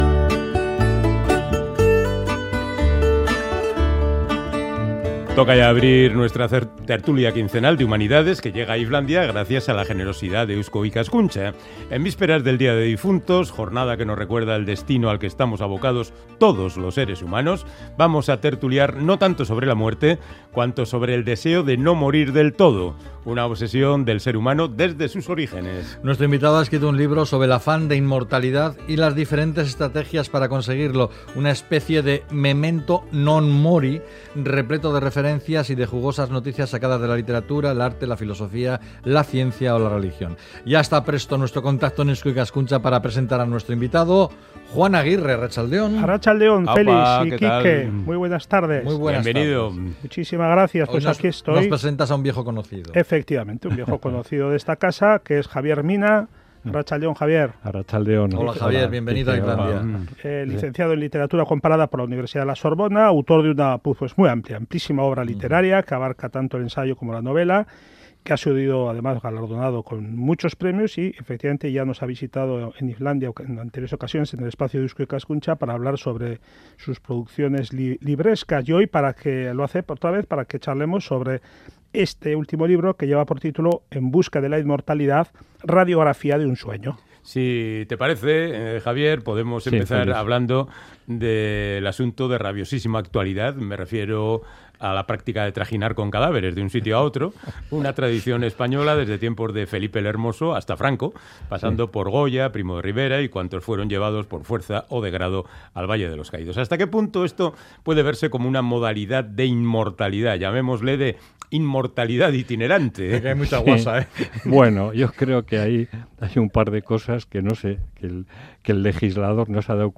irratsaioa_ifl_inmortalidad.mp3